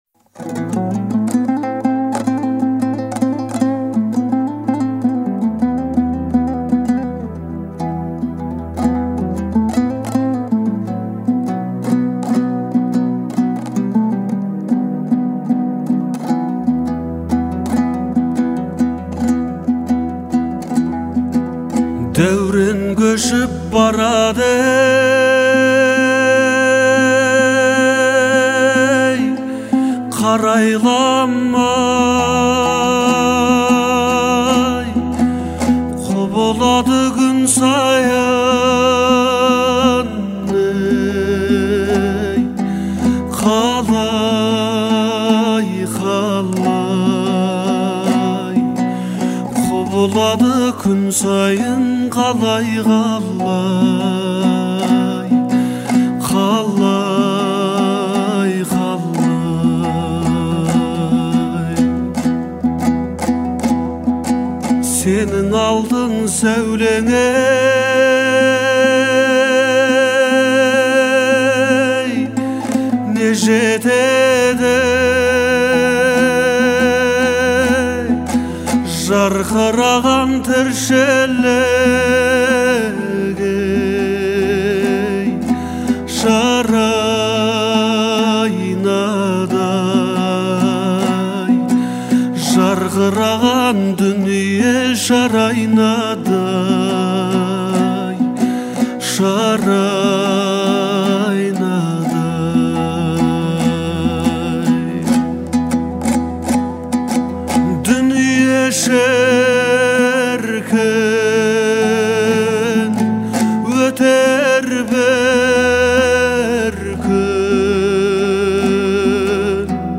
это проникновенная песня в жанре казахской эстрады
обладая выразительным голосом и эмоциональной подачей